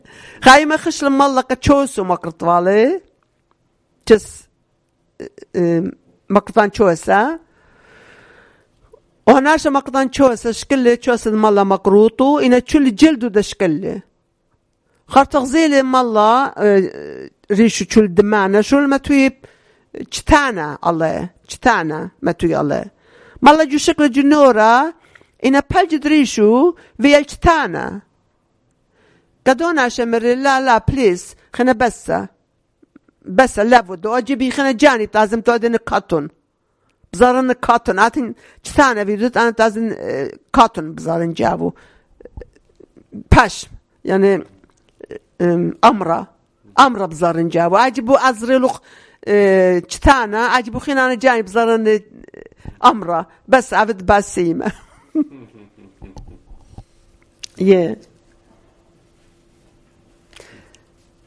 Urmi, Christian: A Close Shave